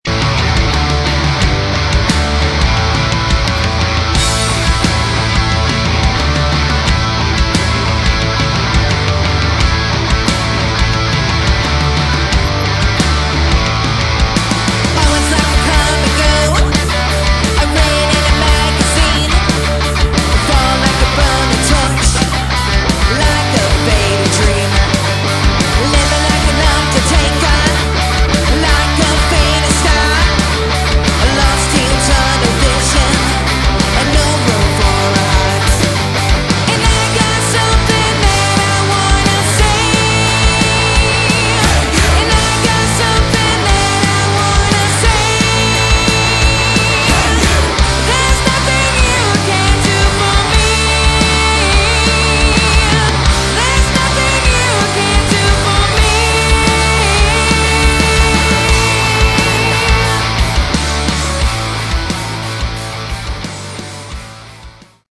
Category: Glam/Punk
vocals
guitar, backing vocals, piano
bass, backing vocals
drums, backing vocals